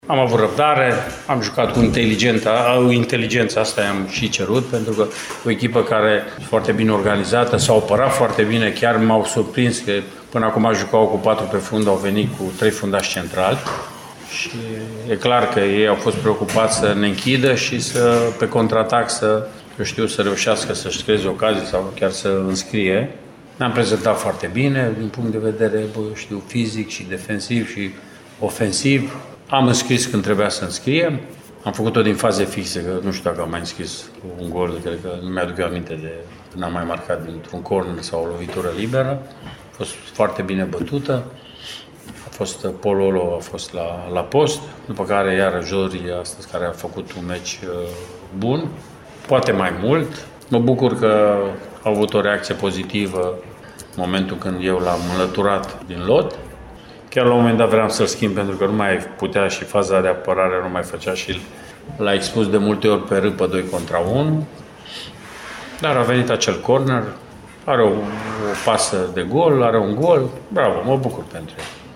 La final, antrenorul Mircea Rednic a apreciat eforturile jucătorilor săi, remarcând chiar faptul că au reușit să marcheze și dintr-o fază fixă, lucru ce nu s-a întâmplat foarte des în acest campionat: